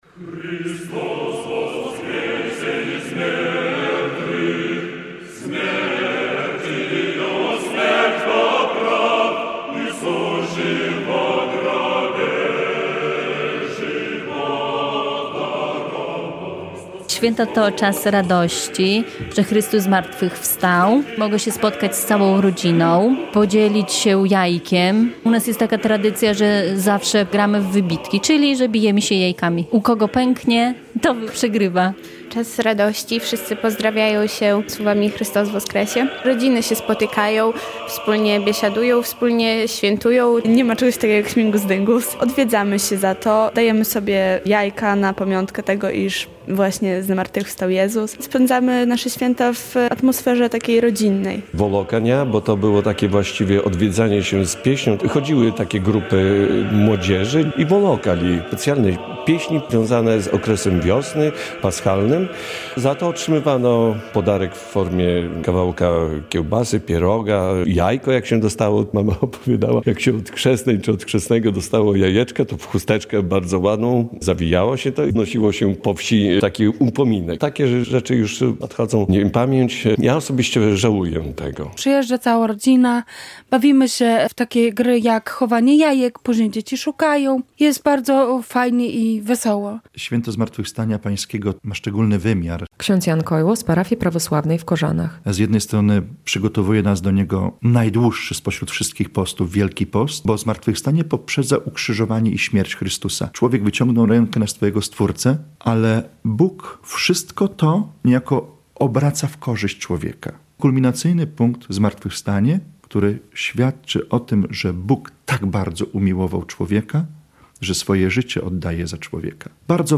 Prawosławni kultywują tradycje wielkanocne swoich przodków - relacja